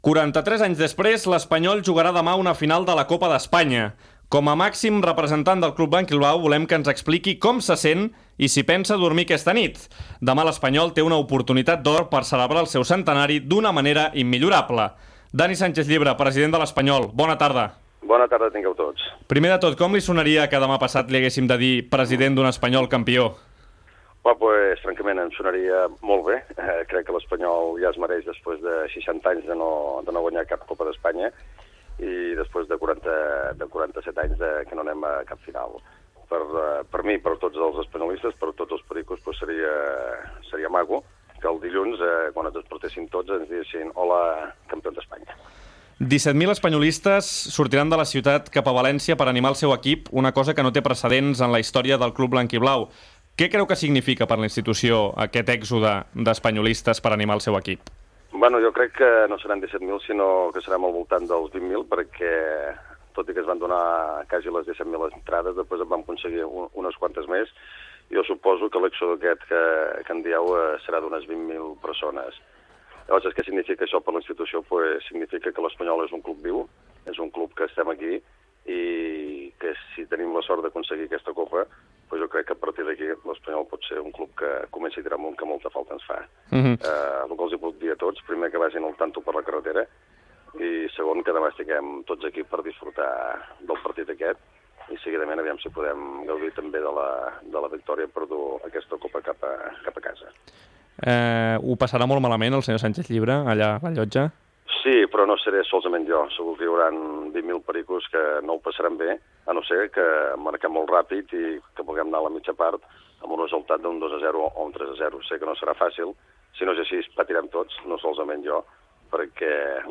Entrevista al president del Real Club Deportiu Espanyol que a l'endemà juga la final de la Copa d'Espanya de futbol masculí (Copa del Rei) a València
Esportiu